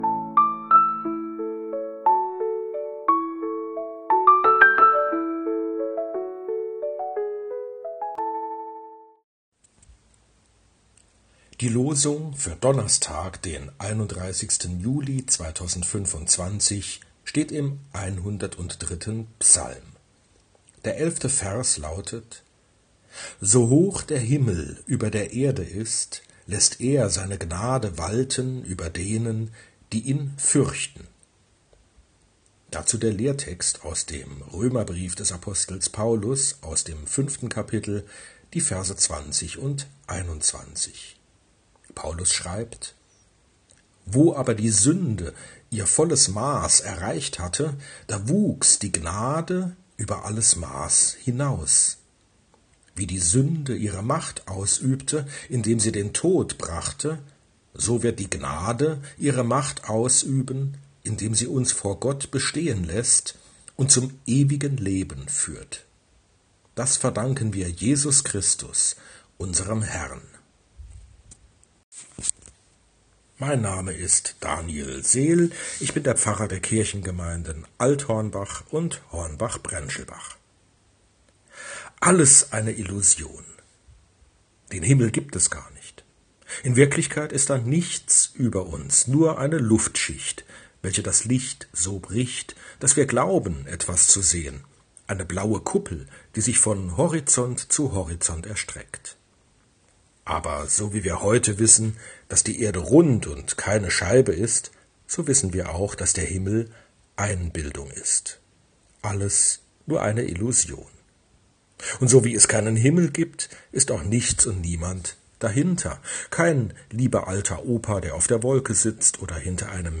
Losungsandacht für Donnerstag, 31.07.2025